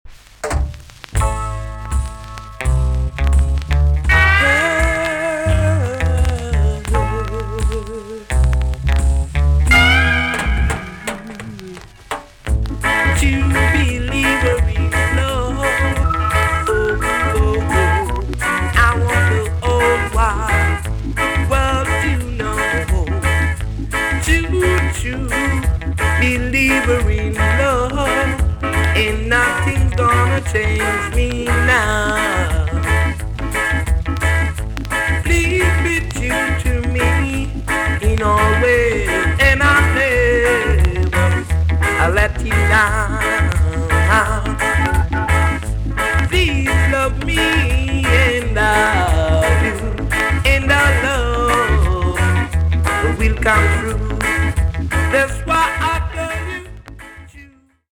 TOP >REGGAE & ROOTS
B.SIDE Version
VG+ 少し軽いチリノイズがあります。